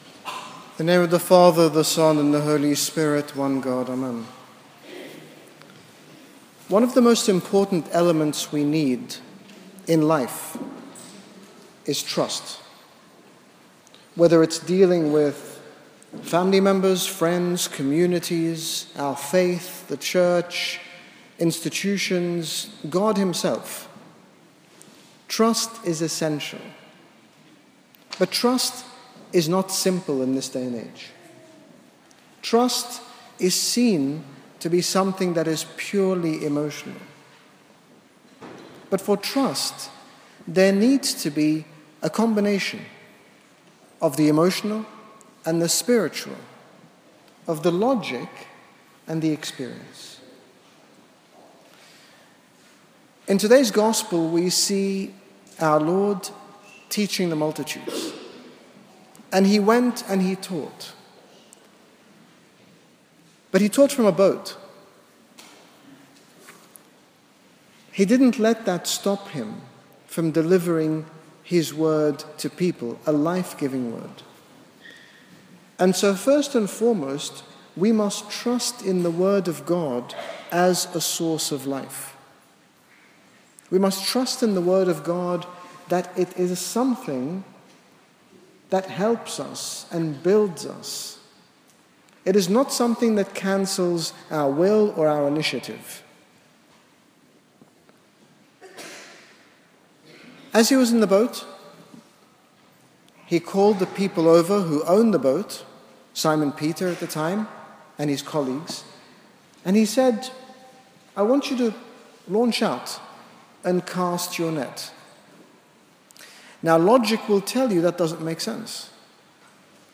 In this short sermon, His Grace Bishop Angaelos, General Bishop of the Coptic Orthodox Church in the United Kingdom, speaks about trusting in the Lord at times, even when we feel a situation may be hopeless. His Grace also speaks about seeing people and our environment through the eyes of Christ so that we can recognise that, despite apparent darkness, there is an abundance of good and righteousness.